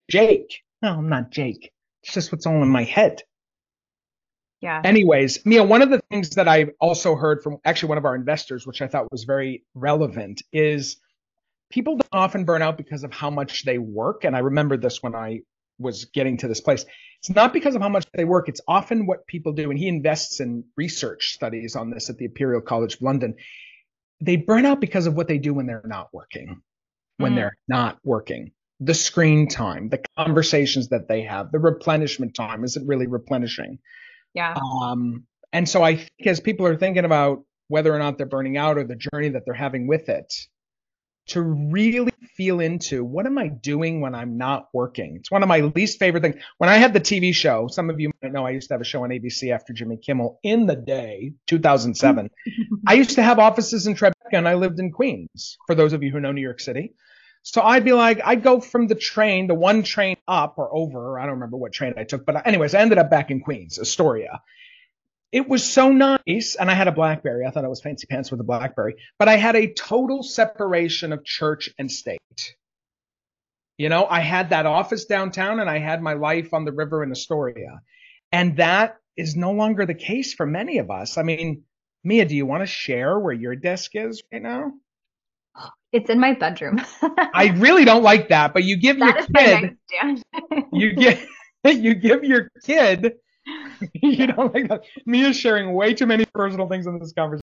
📍 Live on Zoom